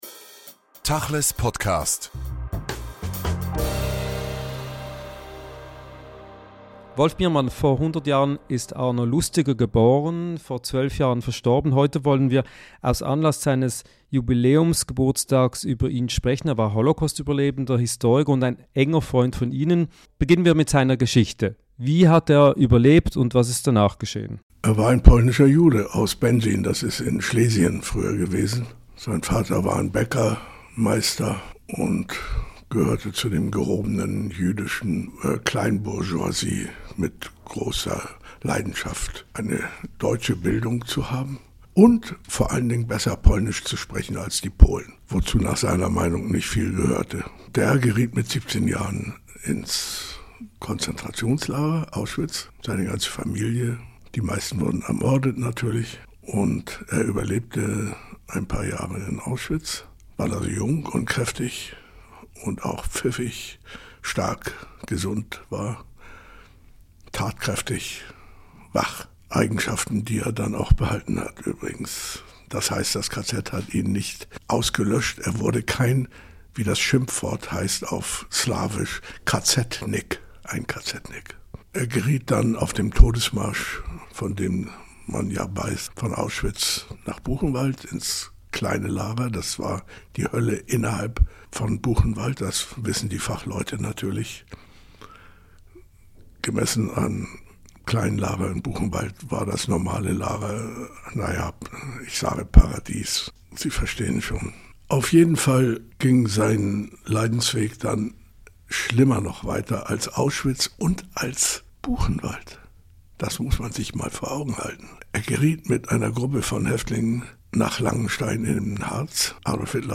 Der Liedermacher Wolf Biermann erinnert im aktuellen Podcast an den Holocaustüberlebenden und Historiker Arno Lustiger, der in diesem Jahr 100. Jahre alt geworden wäre. Ein Gespräch über jüdischen Widerstand, Judentum und Freundschaft.